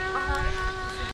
描述：街头音乐家吹喇叭用DS40录制，因为左边的麦克风神秘地停止工作，在Wavosaur中作为单声道录音抢救，有人说"uhoh".